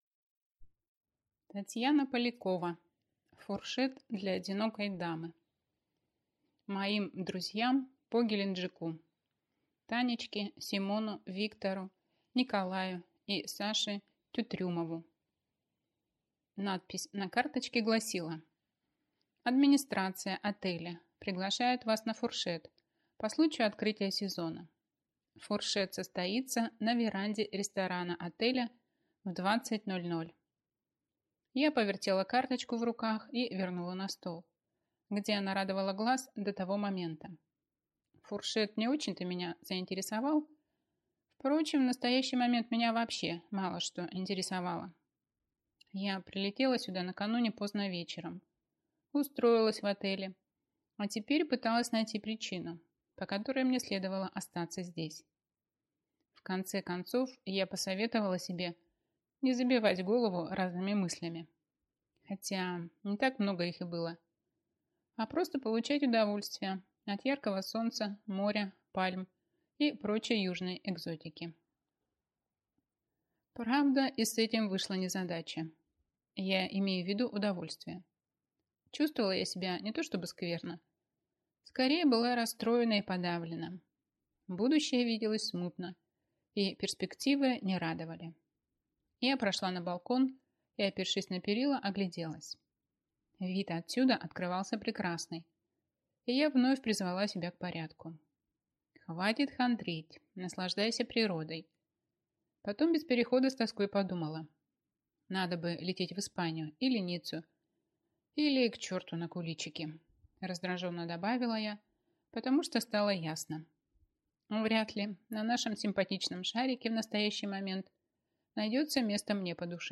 Аудиокнига Фуршет для одинокой дамы | Библиотека аудиокниг